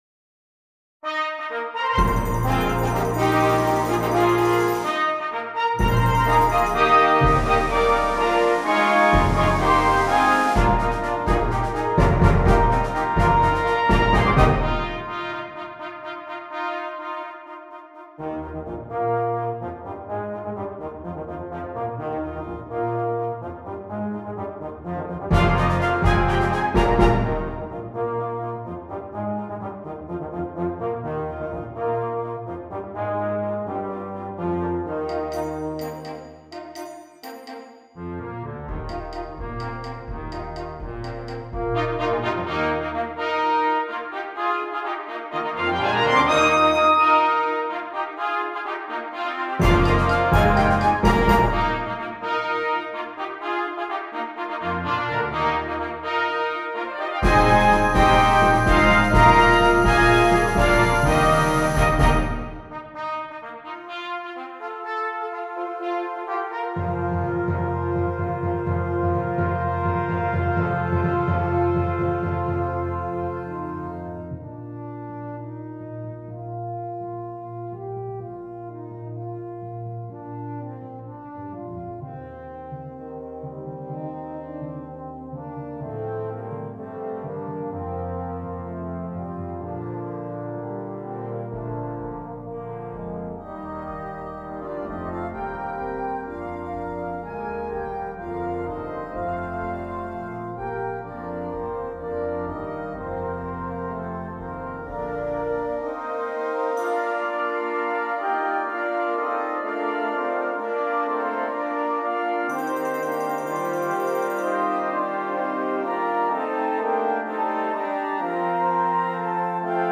Brass Choir (5.4.3.1.1.perc)